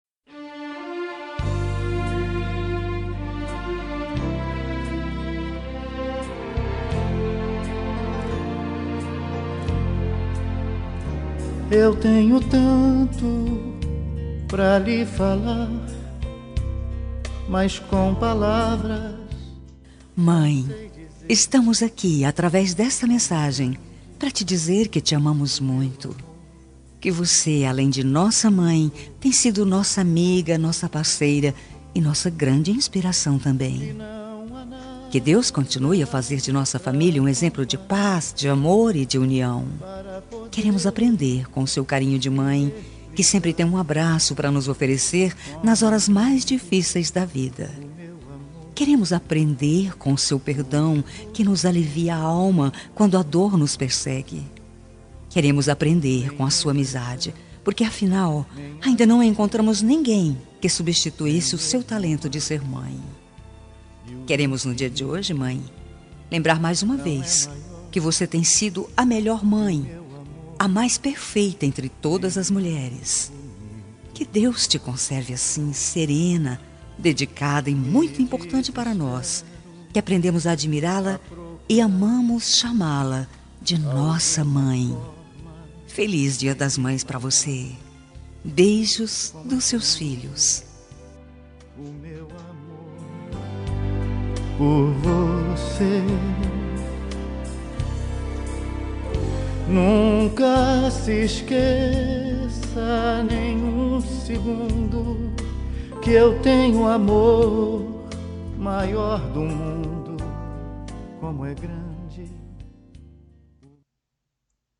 Dia das Mães – Para minha Mãe – Voz Feminina – Plural – Cód: 6506